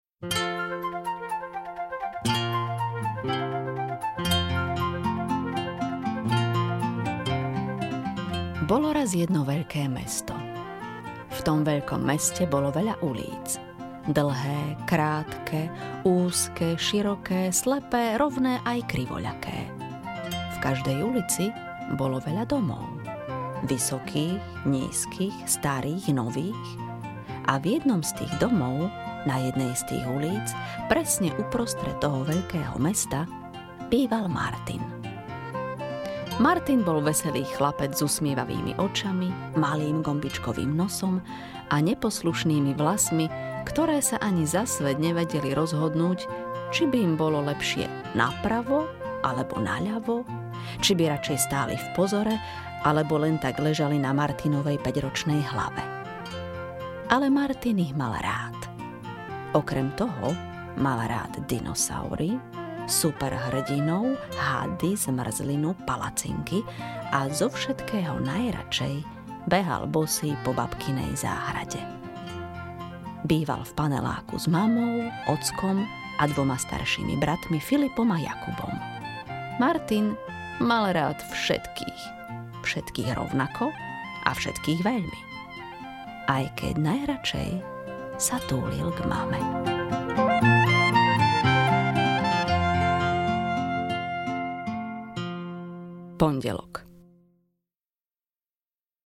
Martin, ten má všetkých rád audiokniha
Ukázka z knihy